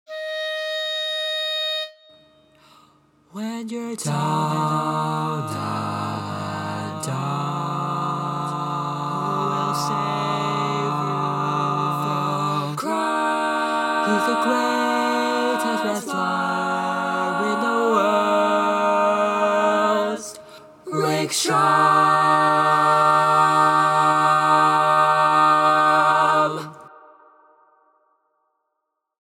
Key written in: E♭ Major
How many parts: 4
Type: Barbershop